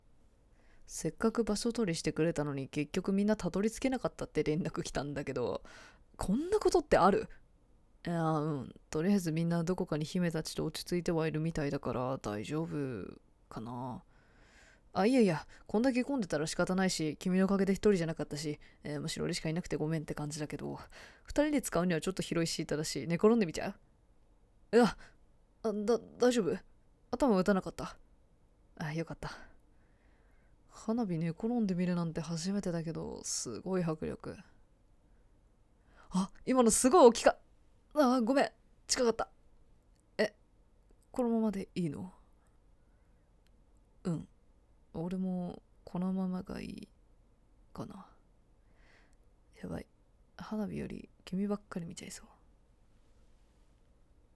ソロ声劇②